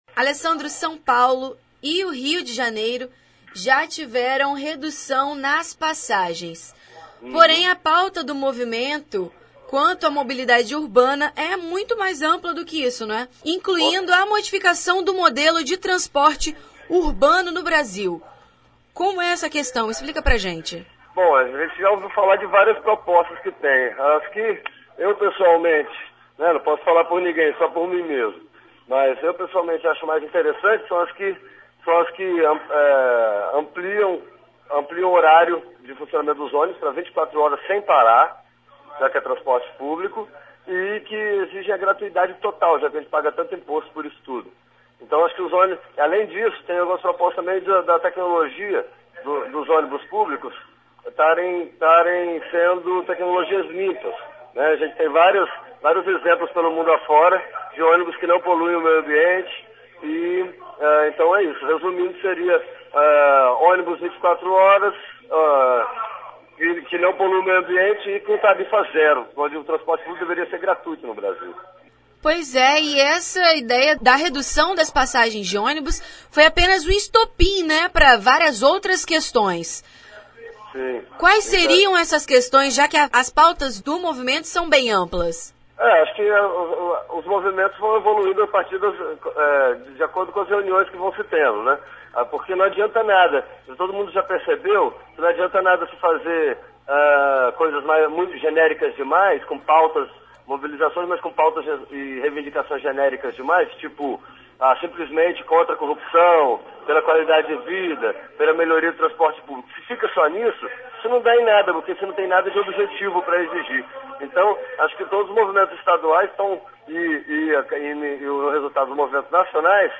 Confira a entrevista com o geógrafo
loc_manifestacao_20_06_editada.mp3